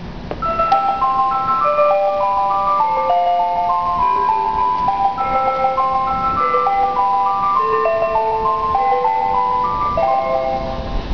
Departure Procedure and Sounds
Yamanote line: In some stations, hypnotizing little melodies are played before departure (e.g. au or